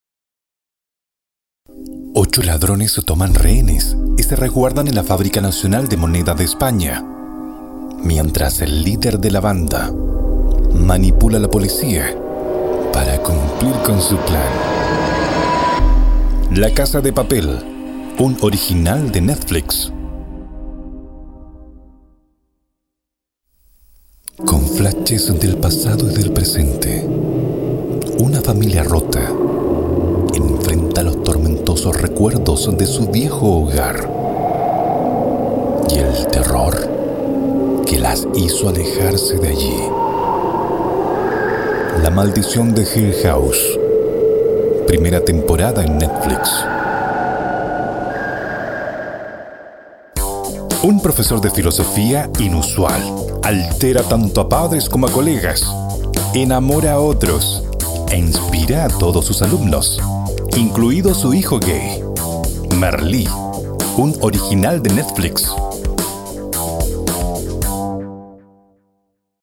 Una voz cálida y con pasión
Sprechprobe: Sonstiges (Muttersprache):